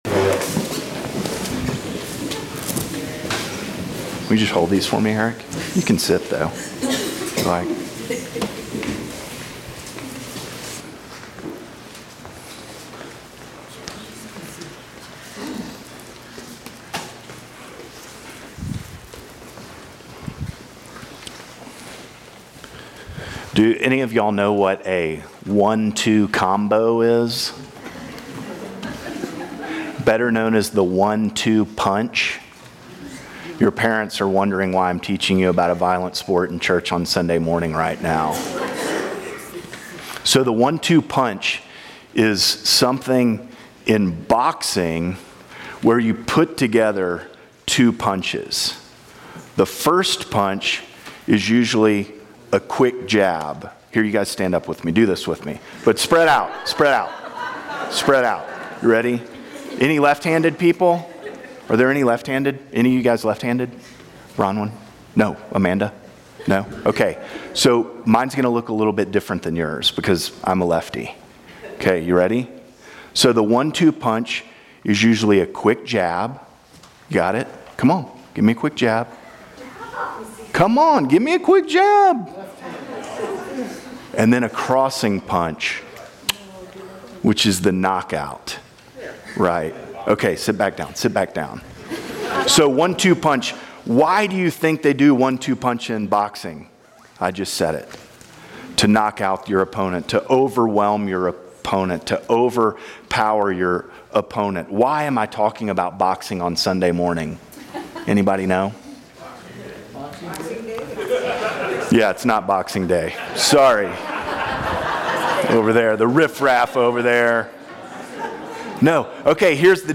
Children's Sermon Trinity Sunday